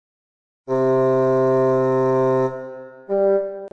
• il primo è più lungo;
• il secondo è più breve.